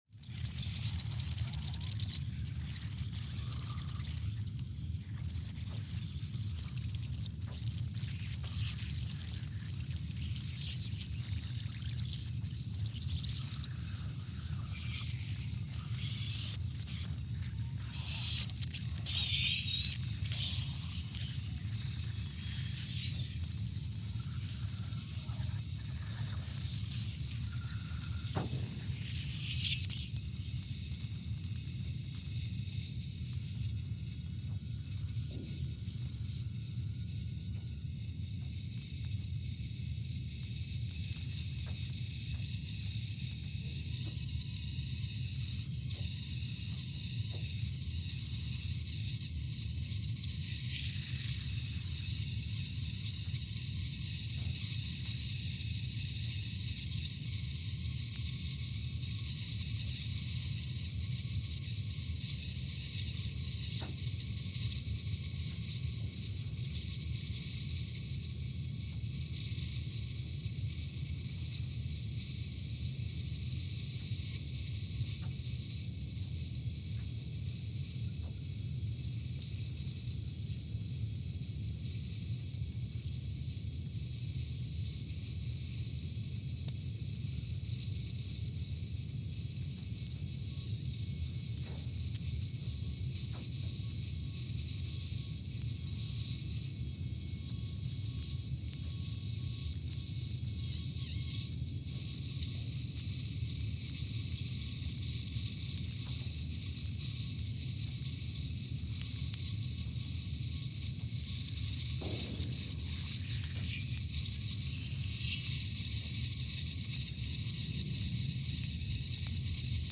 Scott Base, Antarctica (seismic) archived on October 24, 2019
Sensor : CMG3-T
Speedup : ×500 (transposed up about 9 octaves)
Loop duration (audio) : 05:45 (stereo)
SoX post-processing : highpass -2 90 highpass -2 90